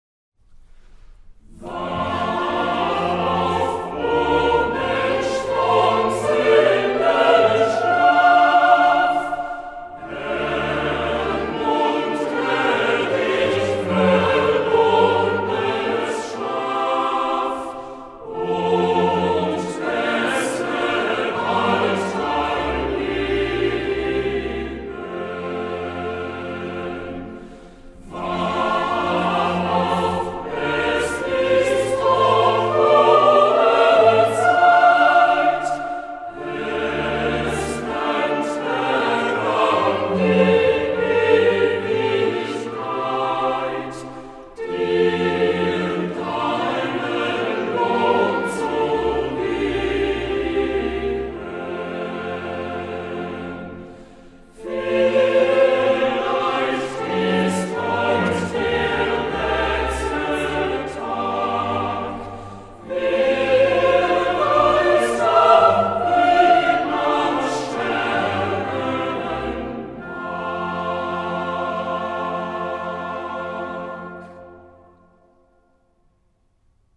3donner lent(passion).mp3